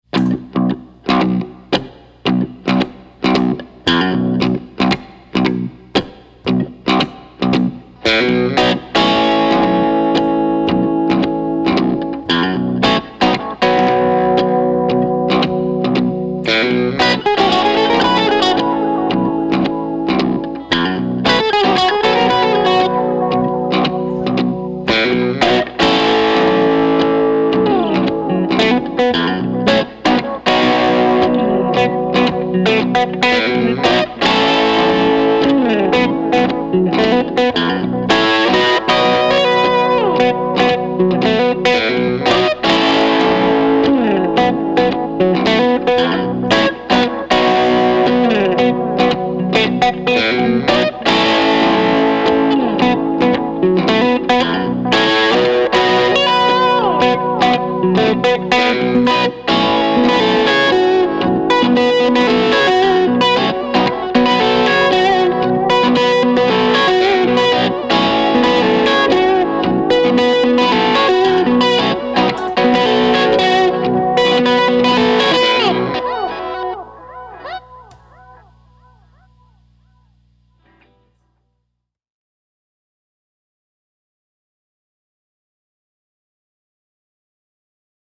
A solo guitar reimagining